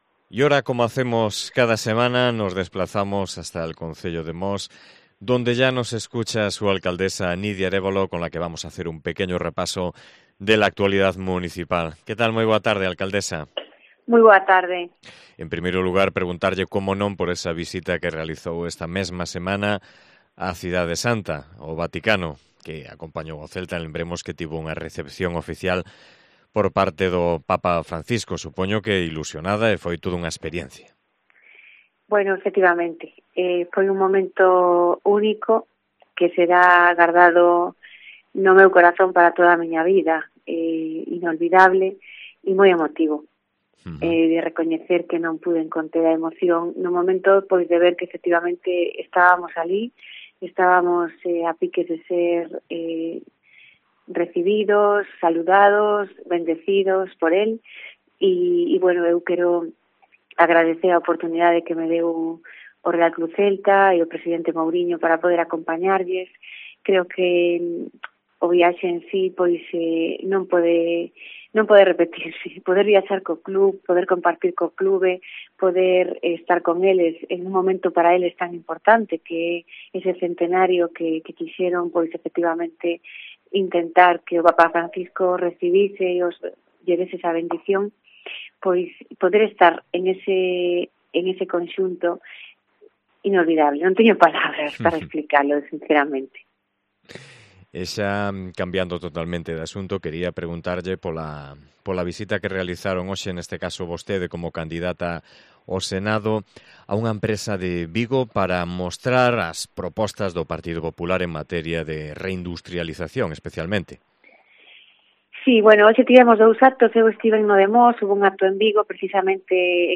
Entrevista con Nidia Arévalo, alcaldesa de Mos